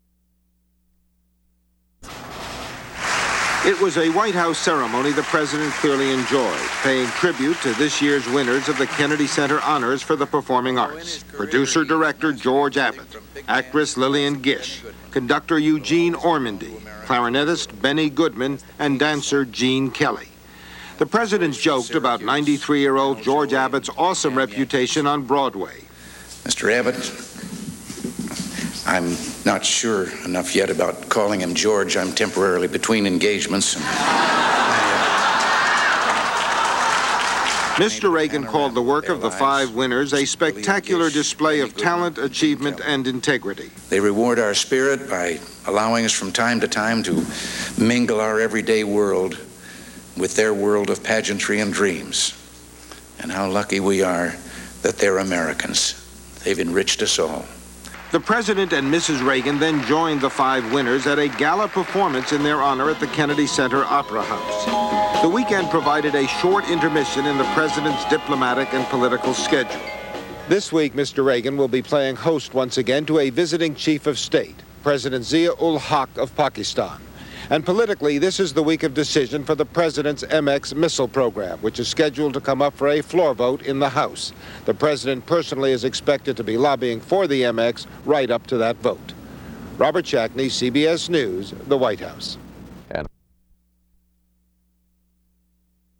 President Ronald Reagan jokes about being "between engagements" at the Kennedy Center awards for the performing arts.
Broadcast on CBS-TV (Monday Morning), December 6, 1982.